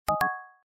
На этой странице представлены звуки восклицательного знака в разных стилях и вариациях: от стандартных системных уведомлений до необычных интерпретаций.
2 Восклицательный знак